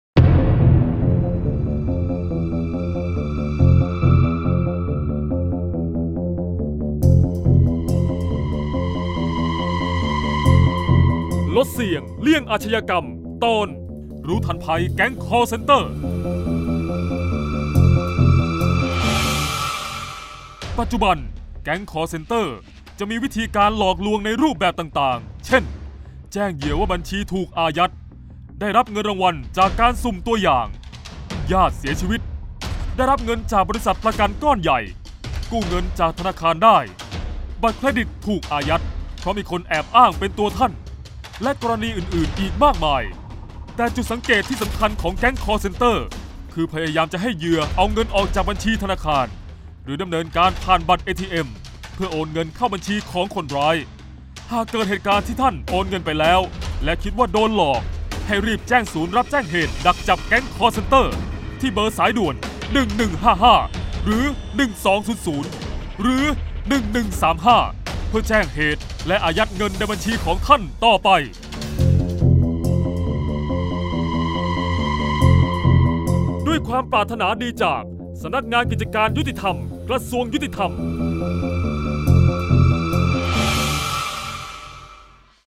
เสียงบรรยาย ลดเสี่ยงเลี่ยงอาชญากรรม 08-รู้ทันแก๊งคอลเซนเตอร์